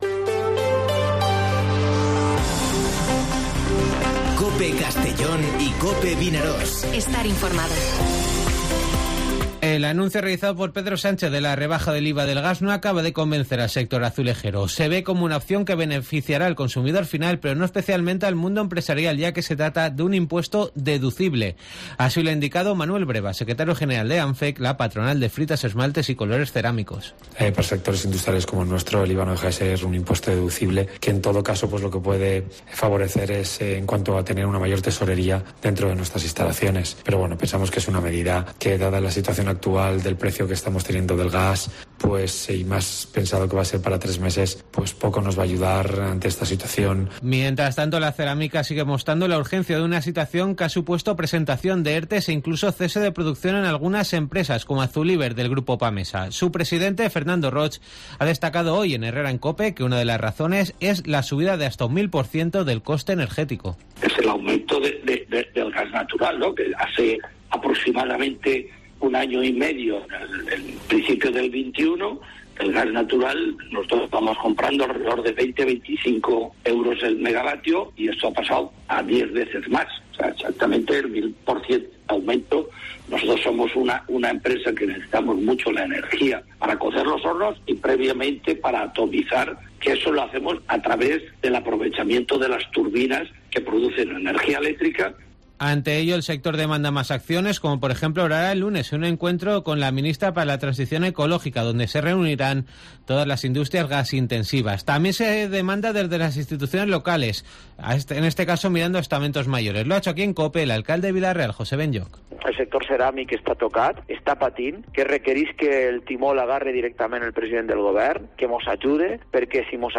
Informativo Mediodía COPE en Castellón (02/09/2022)